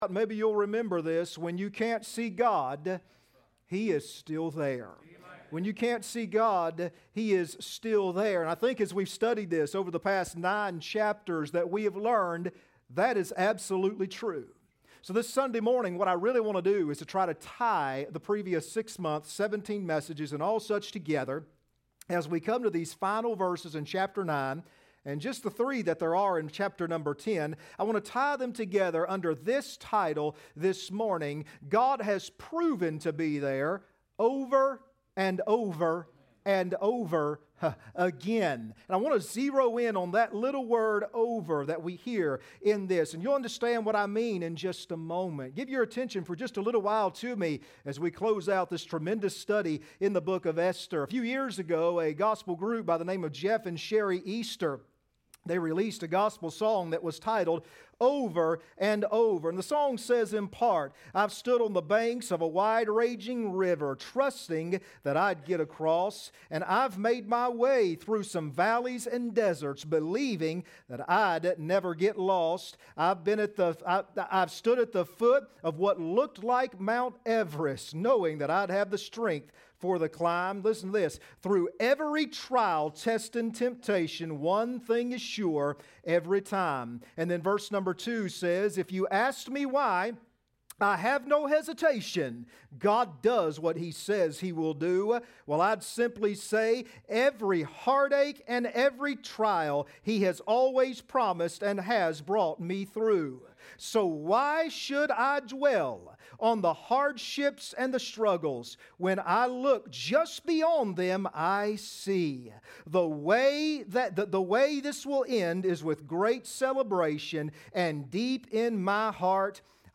Passage: Esther 9:20-22 Service Type: Sunday Morning Next Sermon